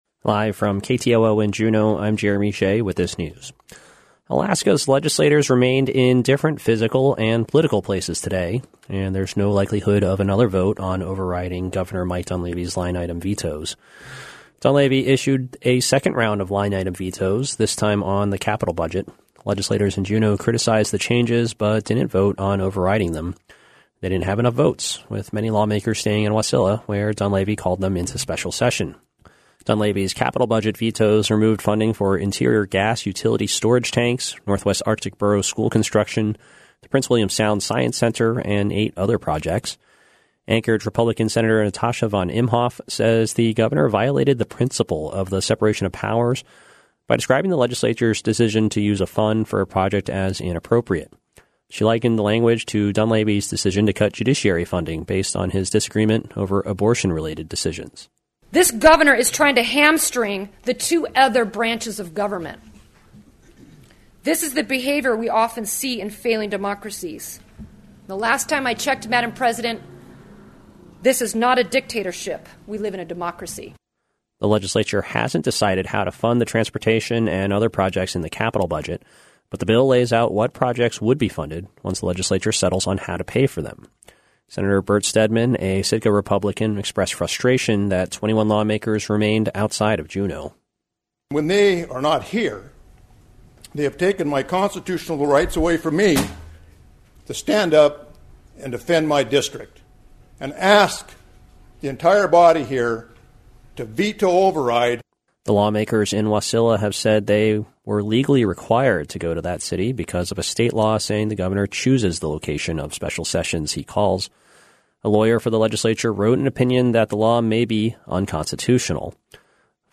Newscast – Thursday, July 11, 2019